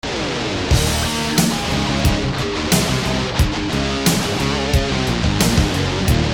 Hard Rock
Nor do we know what kinds of guitars were used…We only know that the guitar tracks were recorded direct using only a SansAmp Classic and standard studio outboard EQ and reverb equipment.
SA-Hrock.mp3